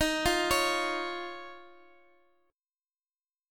Listen to Eb7sus2 strummed